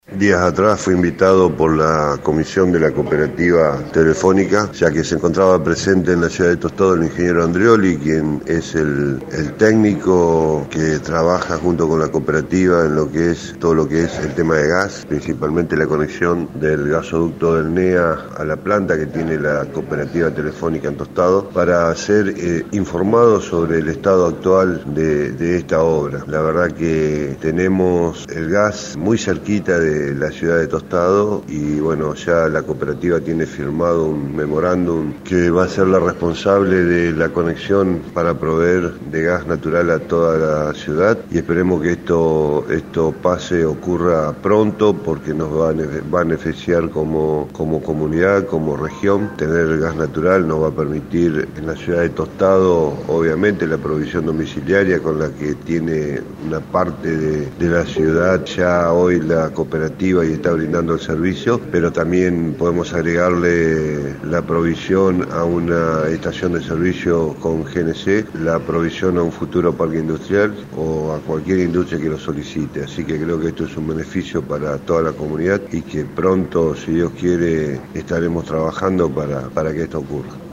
El intendente Enrique Mualem dio más detalles del reciente encuentro: